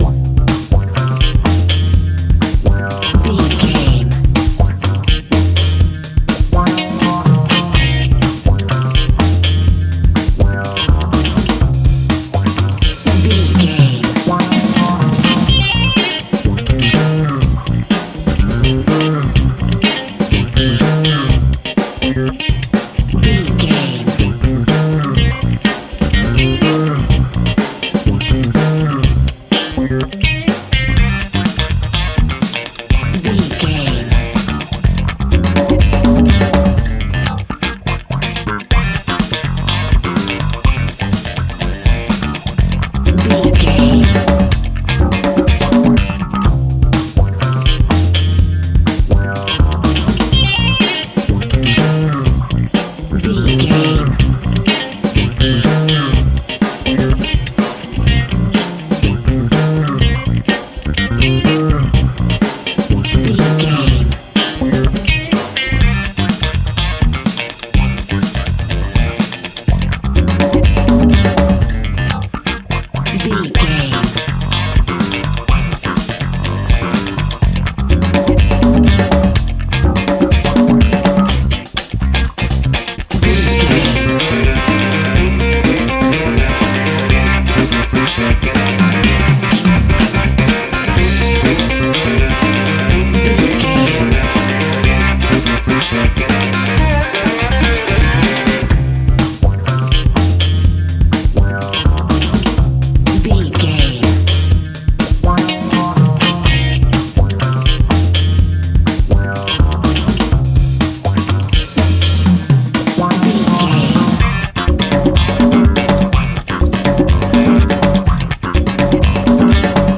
Aeolian/Minor
groovy
funky
lively
electric guitar
electric organ
drums
bass guitar
saxophone
percussion